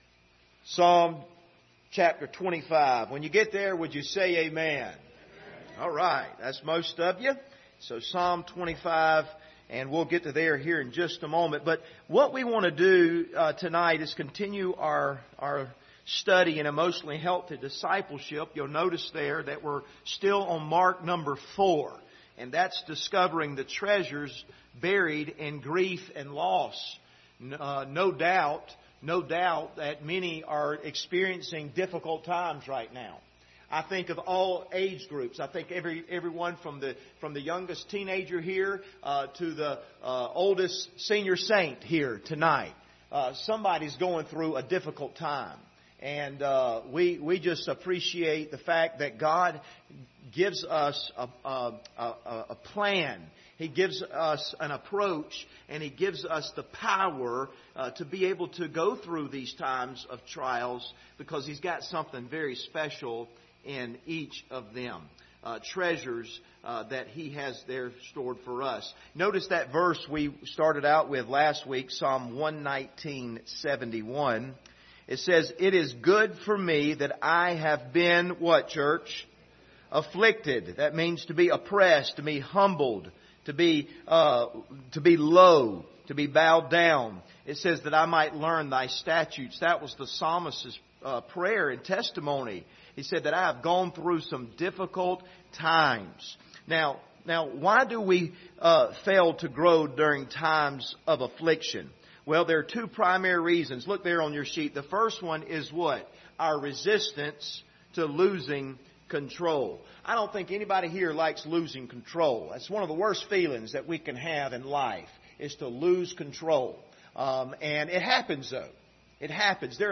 Passage: Psalm 25 Service Type: Wednesday Evening